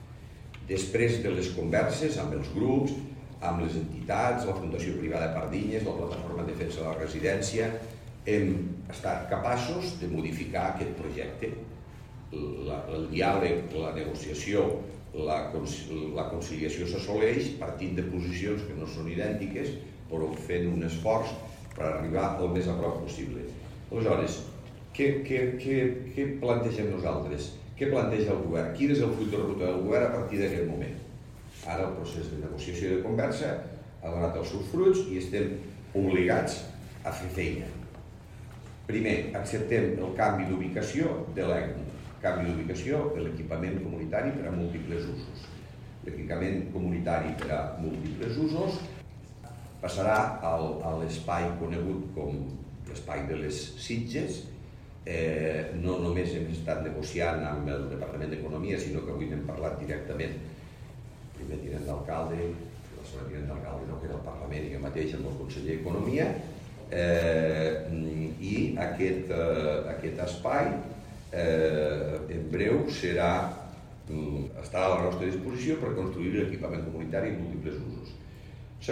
(354.7 KB) Tall de veu de l'alcalde Miquel Pueyo sobre el canvi d'ubicació de l'ECMU.
tall-de-veu-de-lalcalde-miquel-pueyo-sobre-el-canvi-dubicacio-de-lecmu